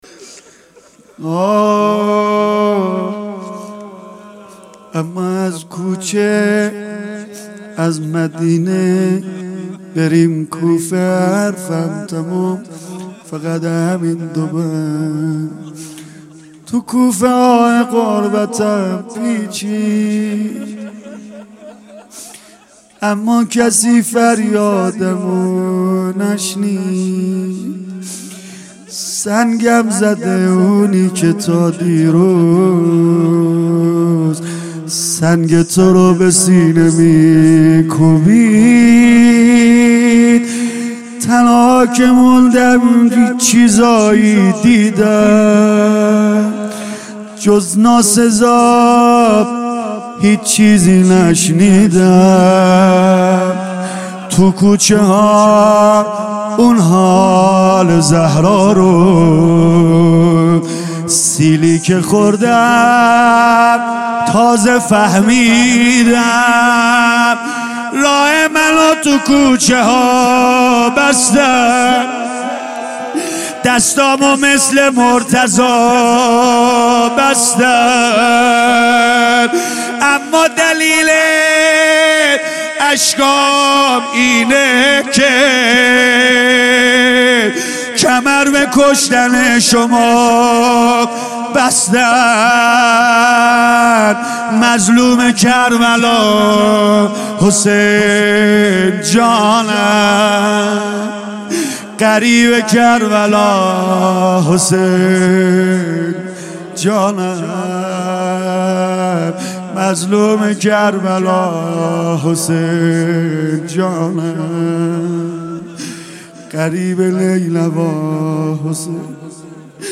مداحی جدید
شب اول محرم